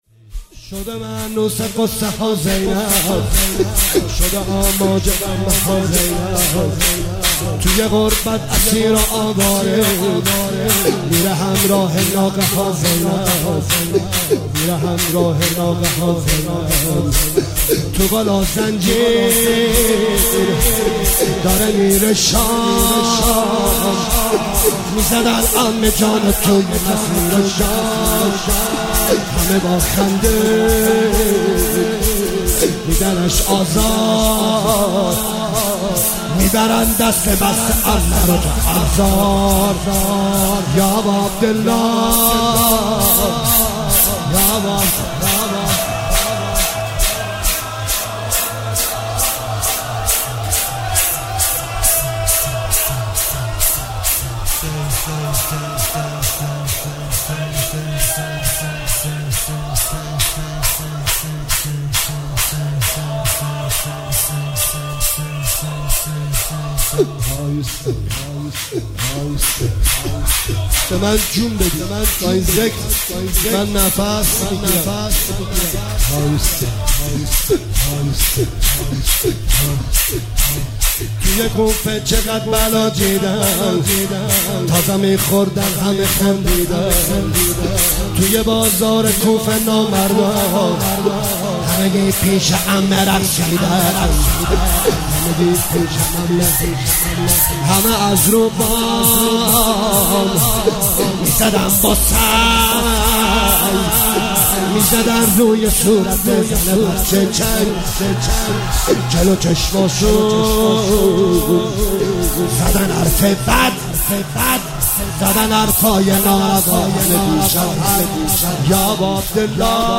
مداحی شور لطمه زنی